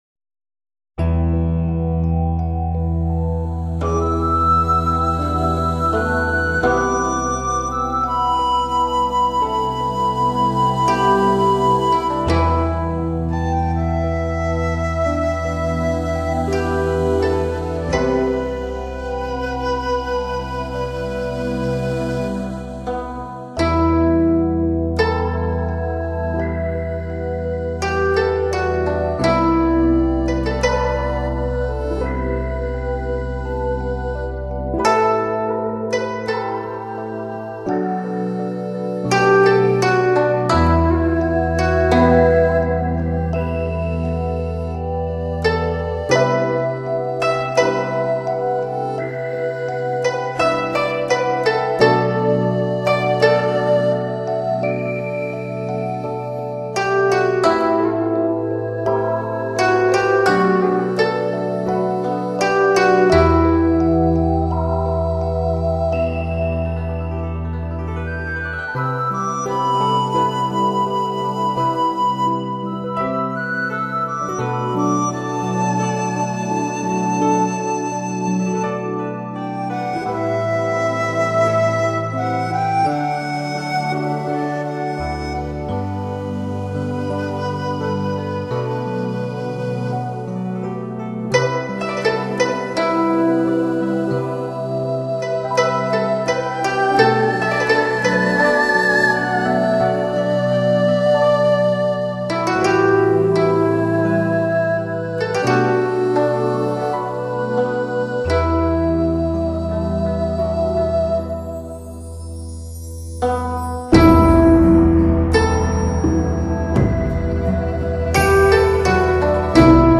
②专辑类别：原声大碟